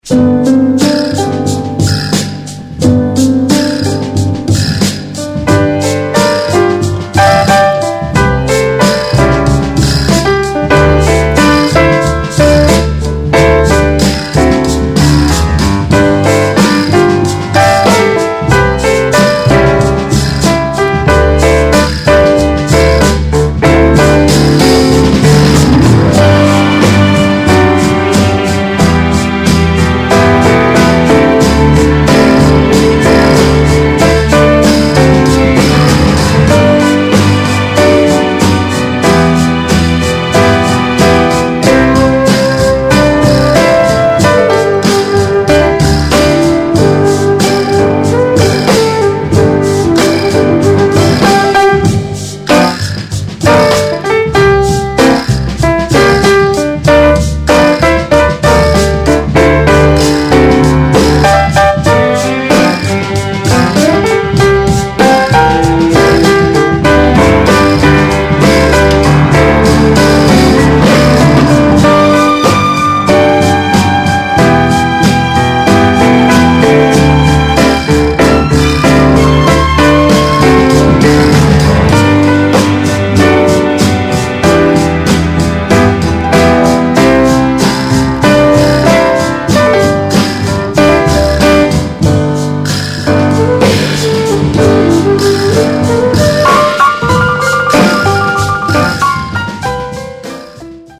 Jazz Funk Classic!!